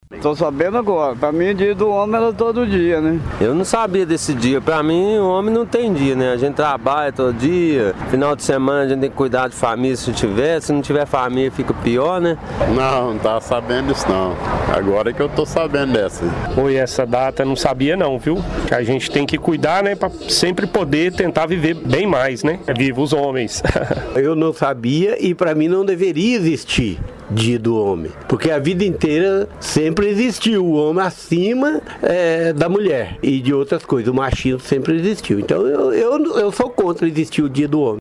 O Jornal da Manhã foi às ruas de Pará de Minas para saber dos homens o que eles acham do assunto.